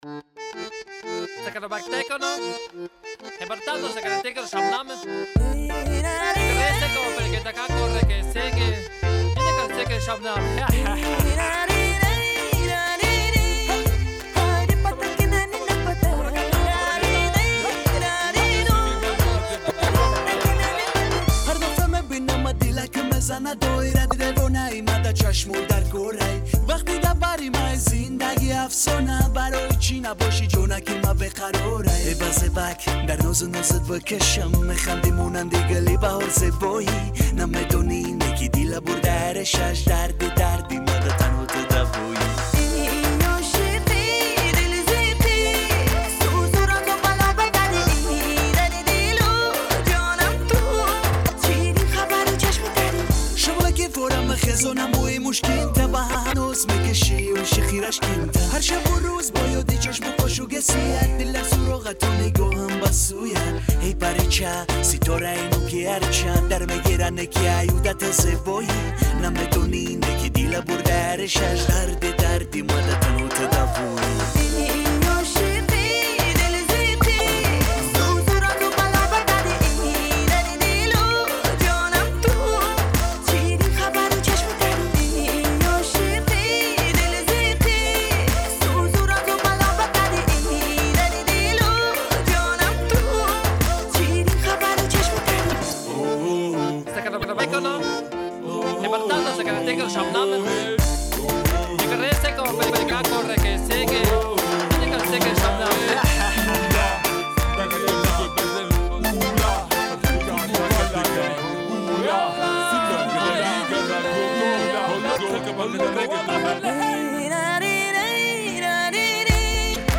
Главная » Музыка » Pop